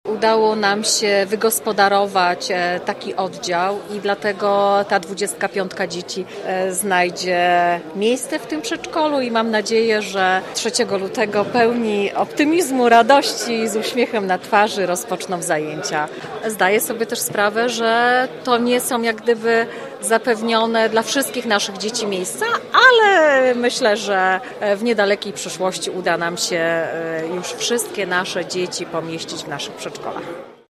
Mówi prezydent Małgorzata Domagała: